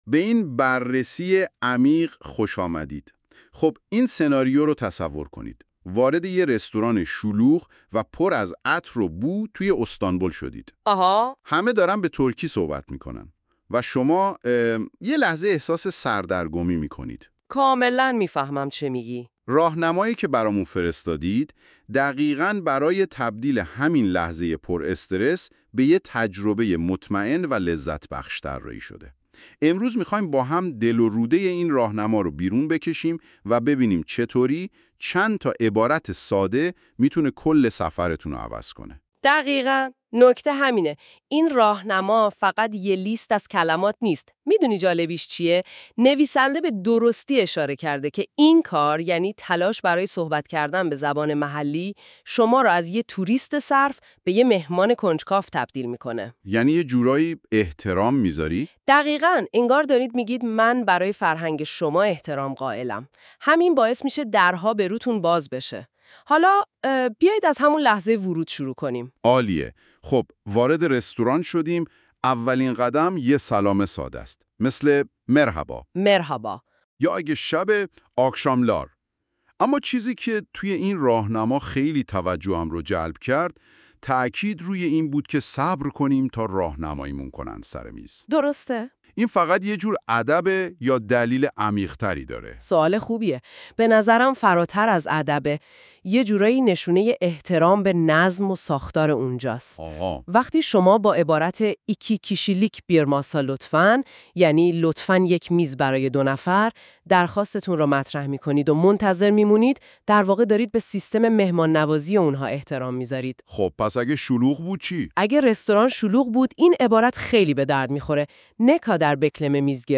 Turkish-restaurant-conversation.mp3